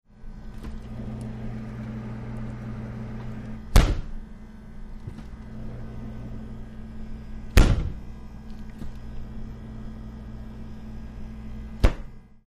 Freezer; Door Open / Close 1; Open / Close With Refrigerator Hum In Background ( Three Times ). Medium Close Perspective. Kitchen.